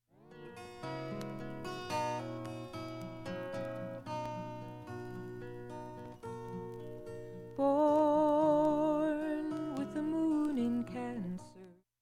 音質良好全曲試聴済み。
A-3始めにかすかなプツが4回出ます
TAS推薦の優秀録音としても知られる名作